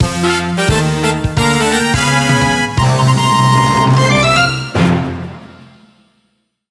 Ripped from the game files
applied fade-out on last two seconds when needed